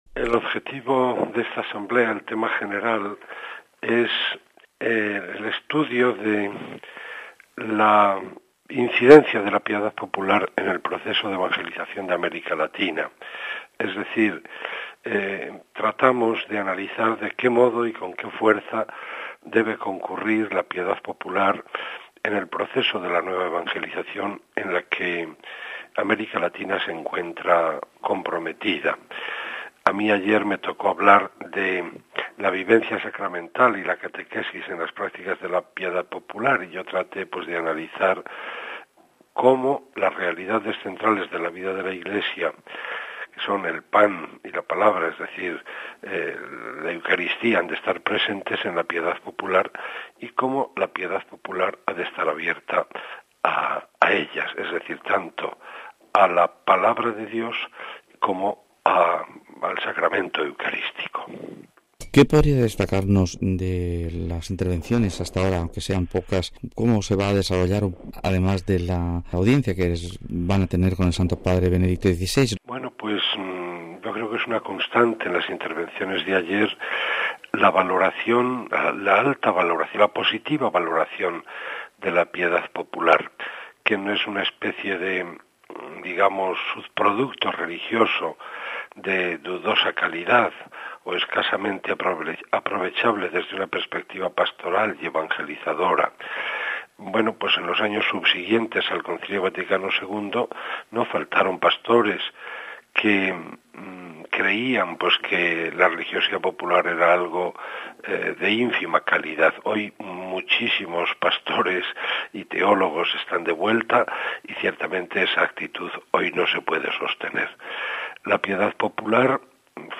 El tema es «La incidencia de la Piedad Popular en el proceso de Evangelización de América Latina». Hoy nos acompaña el arzobispo de Sevilla, Mons. Juan José Asenjo Pelegrina que es miembro de la Pontificia Comisión para America Latina.